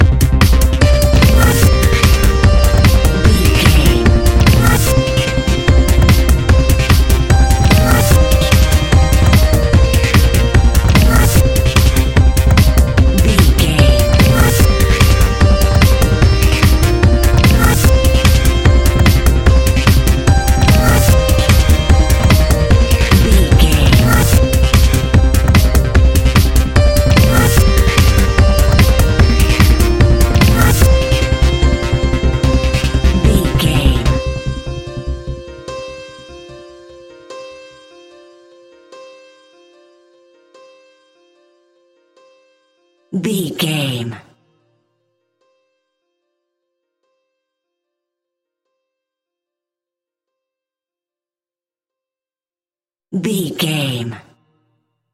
Epic / Action
Fast paced
Aeolian/Minor
Fast
groovy
uplifting
driving
energetic
repetitive
piano
drum machine
synthesiser
acid house
uptempo
synth leads
synth bass